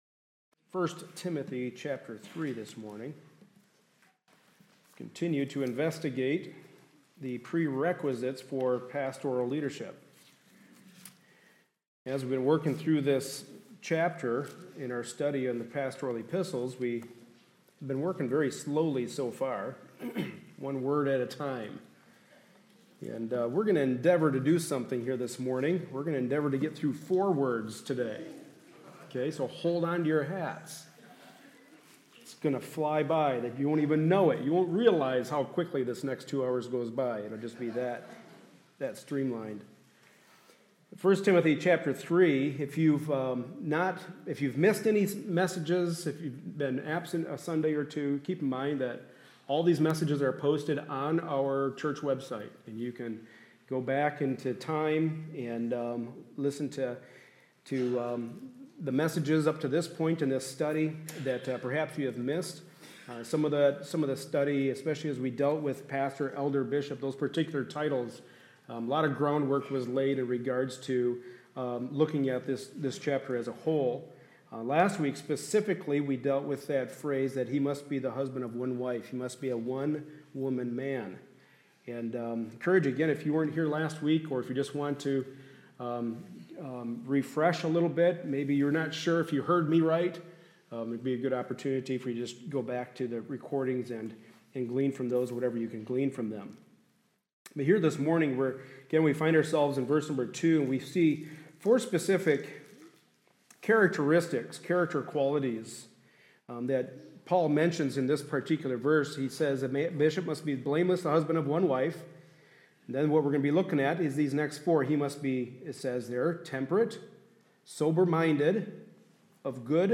1 Timothy 3:1-7 Service Type: Sunday Morning Service A study in the pastoral epistles.